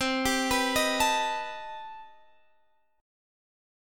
CmM13 Chord
Listen to CmM13 strummed